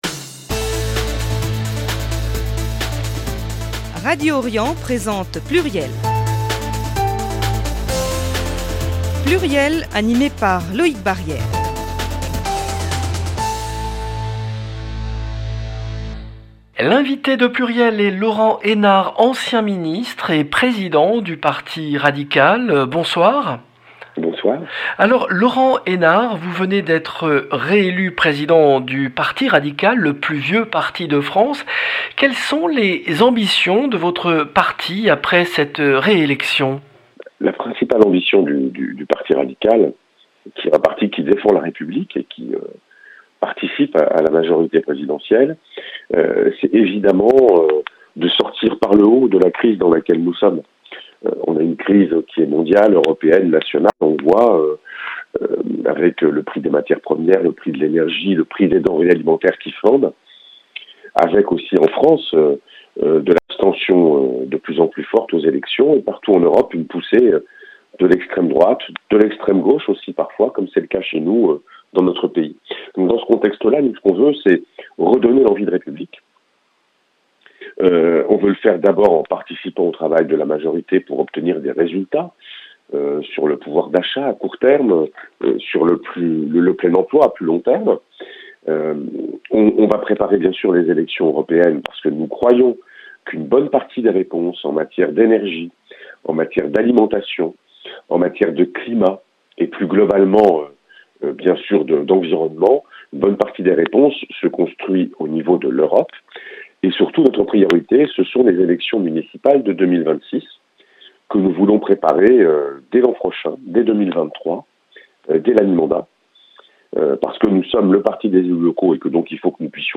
L’invité de PLURIEL lundi 24 octobre 2022 est Laurent Hénart, ancien ministre et président du Parti Radical